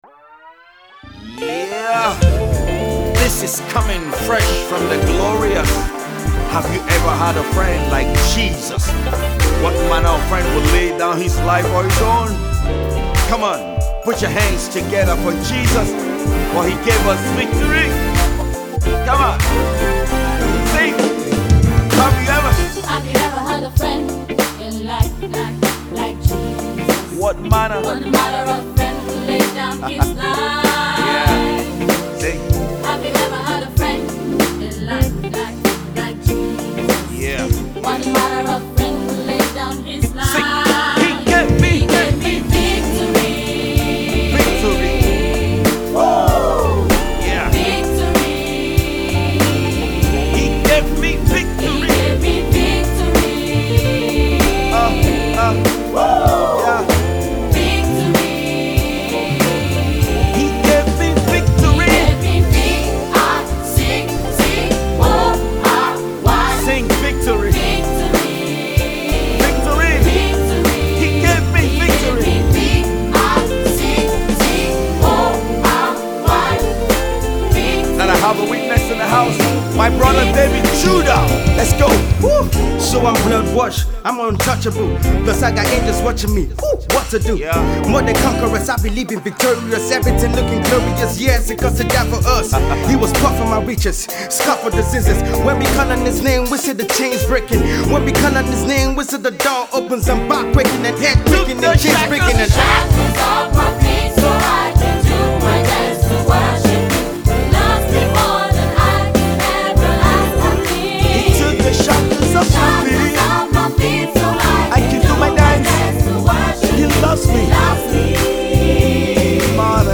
Exemplary Gospel music group
rap